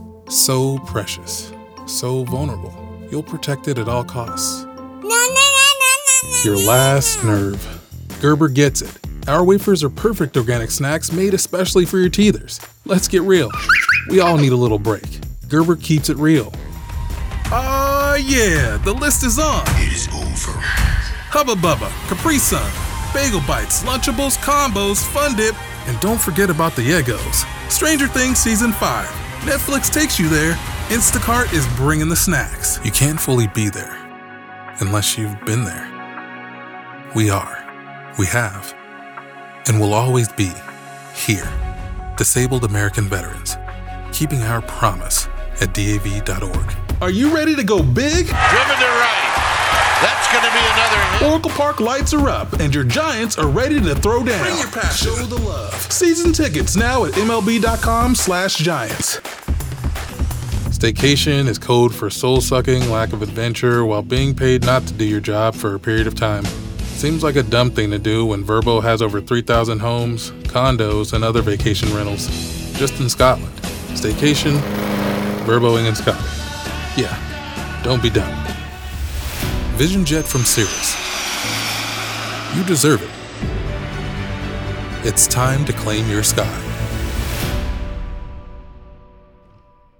American Voice Over Talent
Adult (30-50) | Yng Adult (18-29)
Our voice over talent record in their professional studios, so you save money!